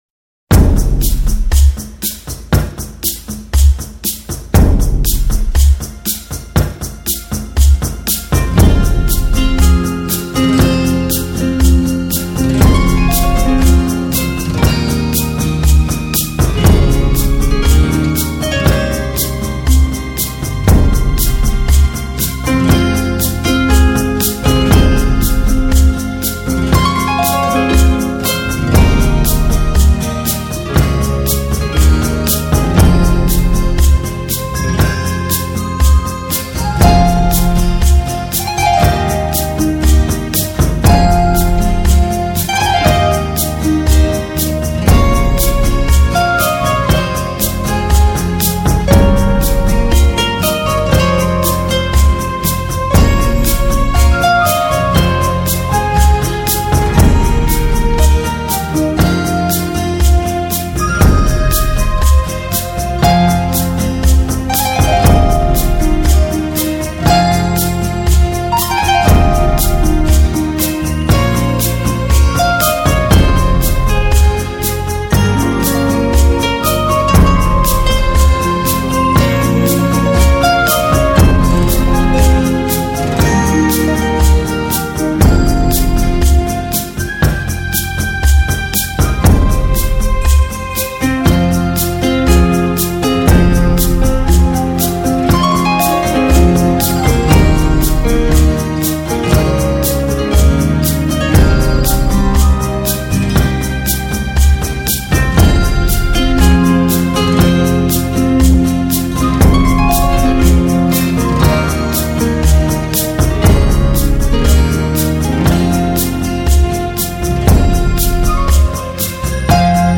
专辑格式：DTS-CD-5.1声道
自然、清新、愉快、有氧。